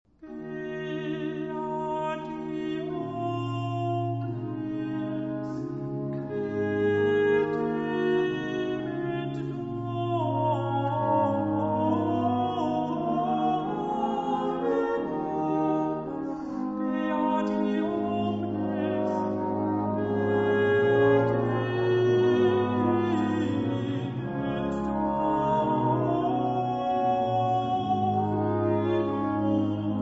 • Registrazione sonora musicale